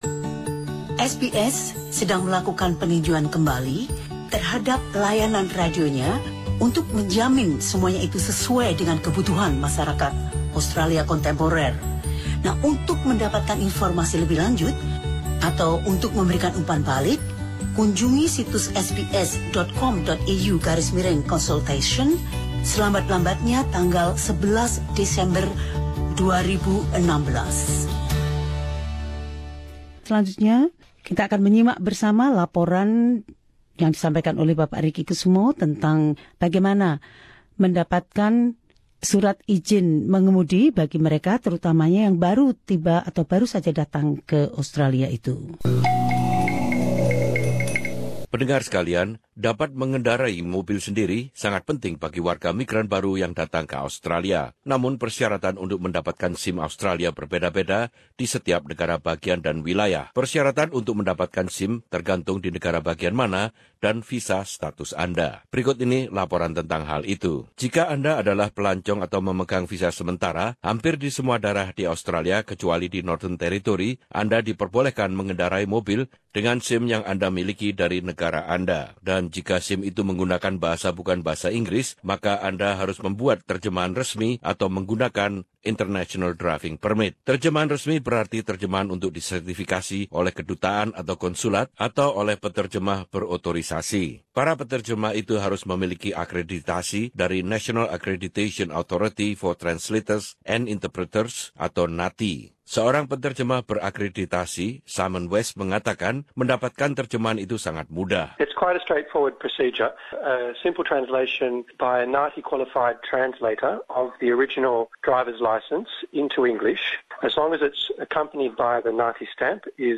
Laporan ini memberikan klarifikasi tentang peraturan dan persyaratan untuk mendapatkan SIM di Australia.